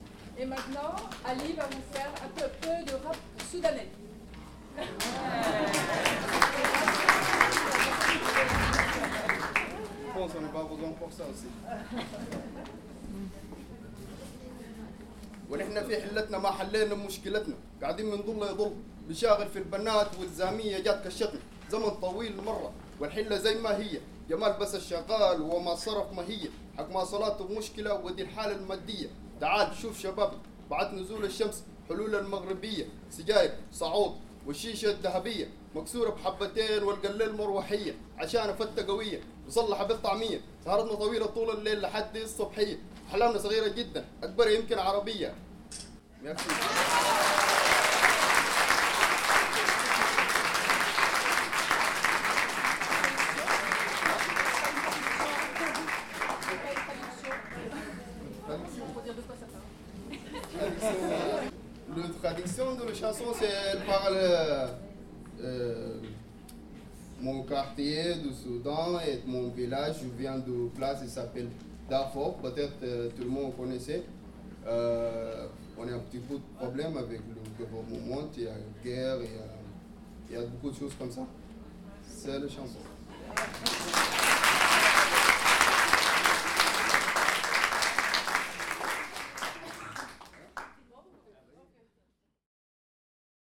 La fête interculturelle !
La fête interculturelle, la bien nommée !
rappeur soudanais